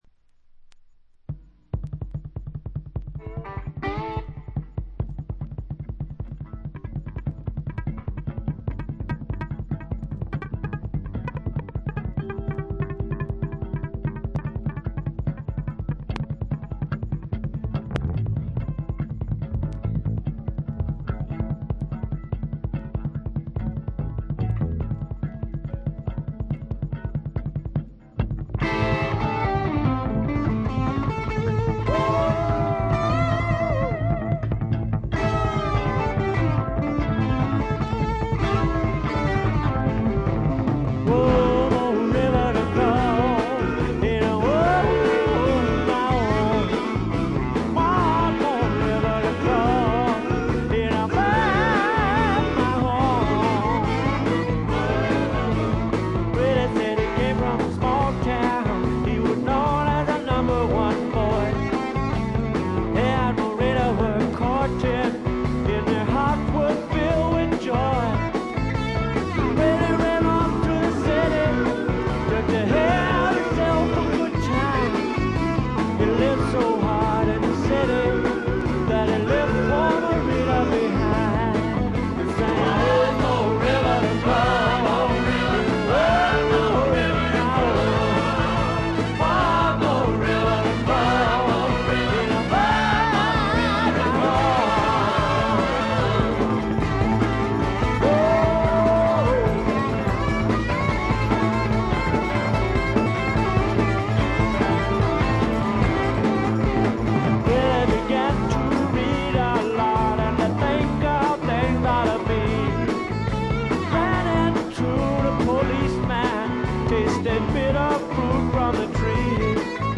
泣けるバラードからリズムナンバーまで、ゴスペル風味にあふれたスワンプロック。
試聴曲は現品からの取り込み音源です。